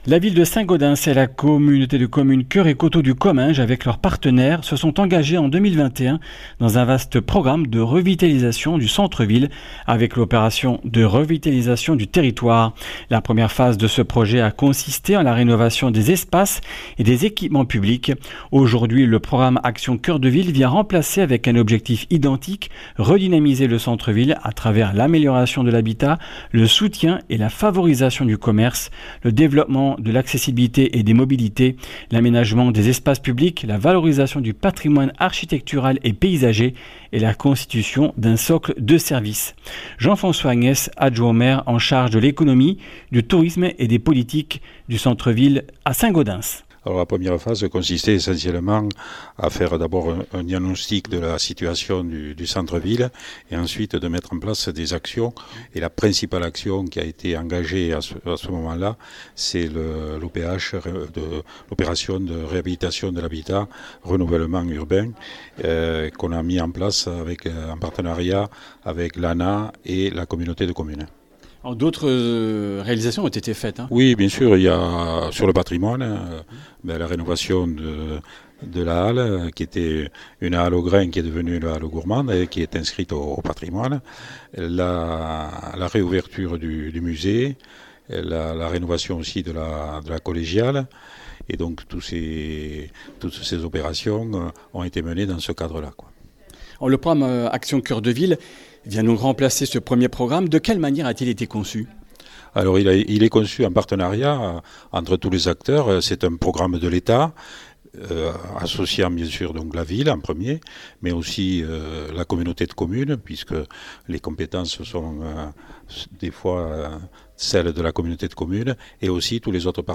Comminges Interviews du 24 juin